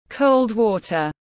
Nước lạnh tiếng anh gọi là cold water, phiên âm tiếng anh đọc là /kəʊld ˈwɔː.tər/
Cold water /kəʊld ˈwɔː.tər/
Cold-water.mp3